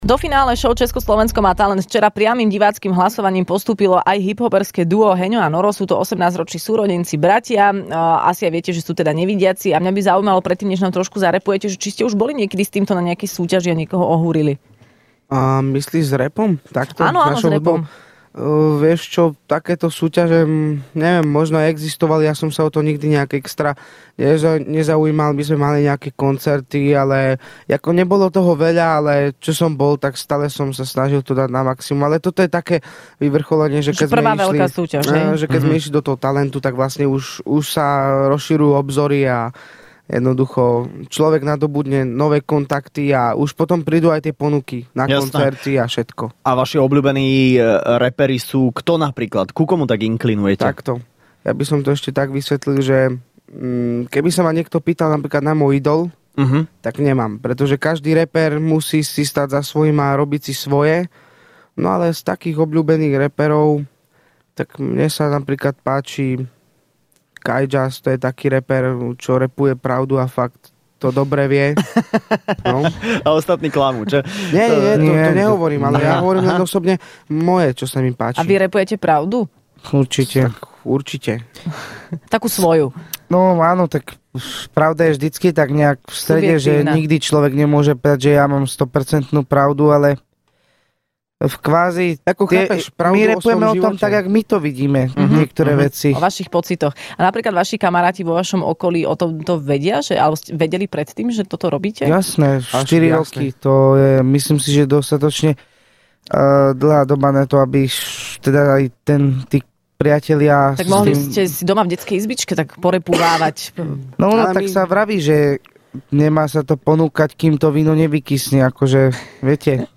V Rannej šou boli hosťami finalisti šou Česko - Slovensko má talent ale okrem nich prišla aj Zuzka Smatanová...
ČSMT II: Chalani dokonca zarappovali kúsok piesne